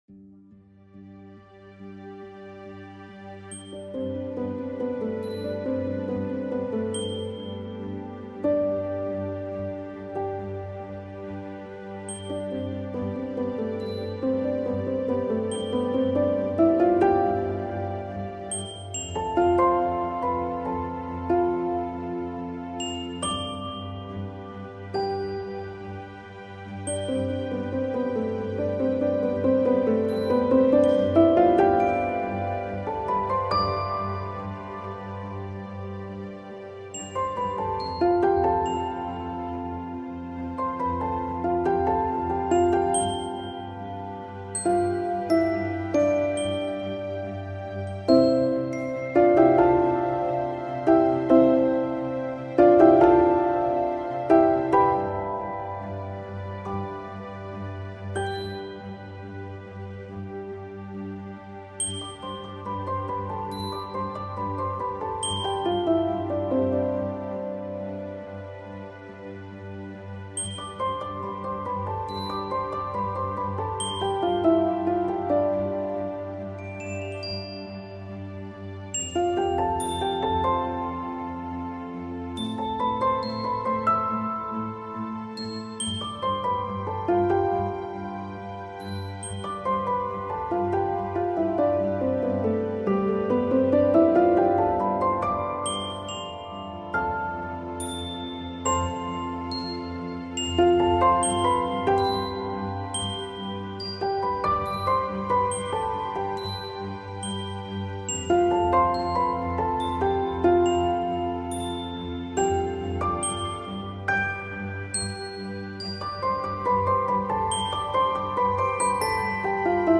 On est dans la découverte et l'insousciance des premières vacances c'est très bien fait avec cette pointe de nostalgie